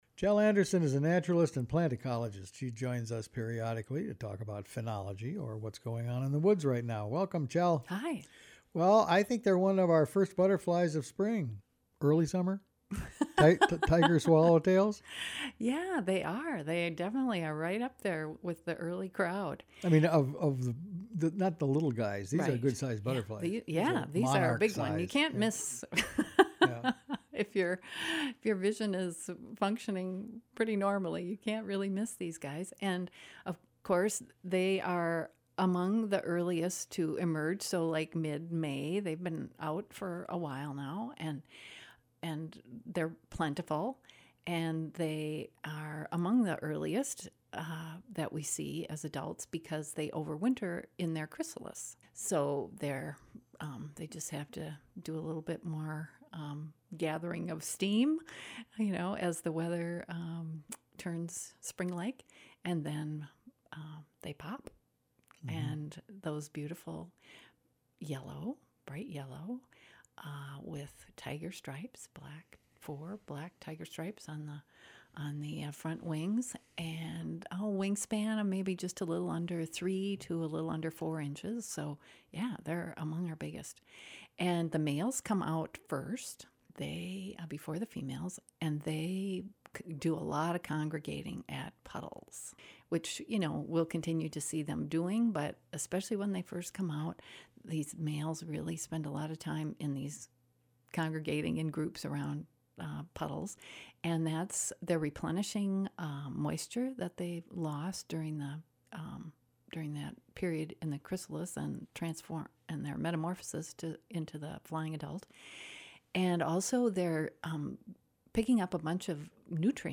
talks with naturalist